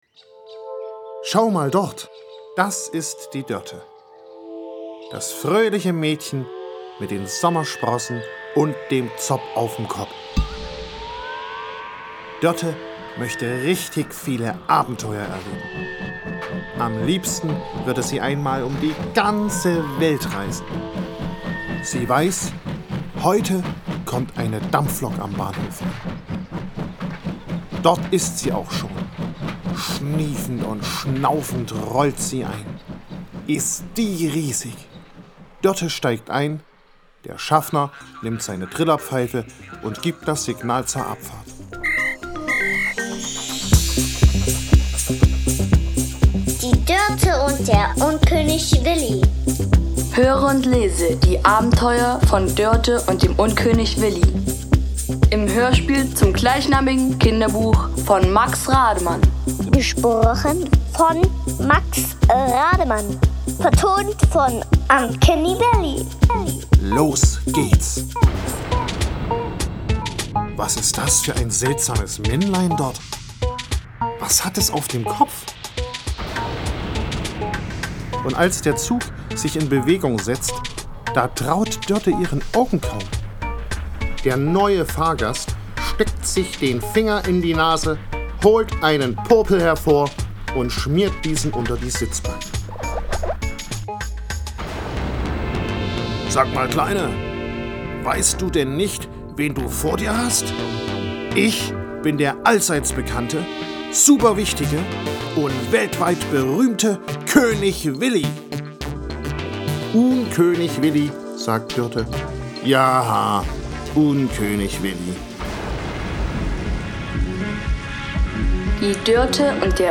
Hörspiel
Im Mittelpunkt stehen hierbei Alltagsklänge und -Geräusche, die in aufgeschlossenen Ohren zu Musik werden. [...] Warum nicht mal House-Musik für die ganz Kleinen?